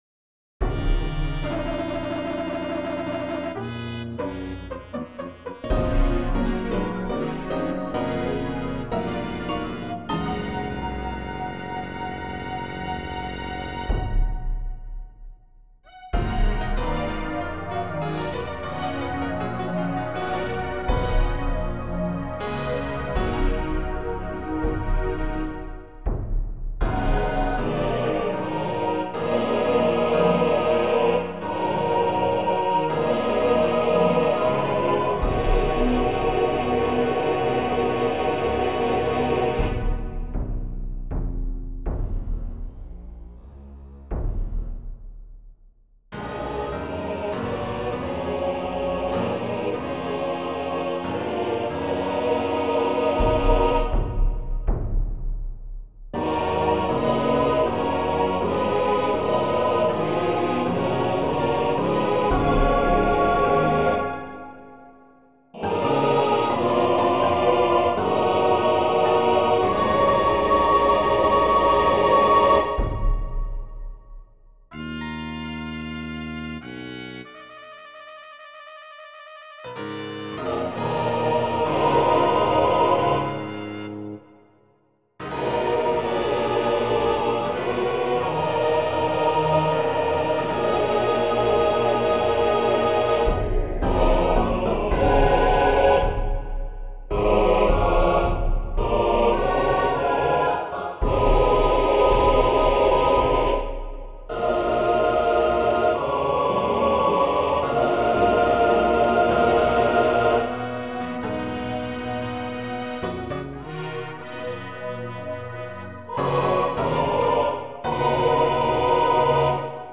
Judge Choirs; fis Major, dis minor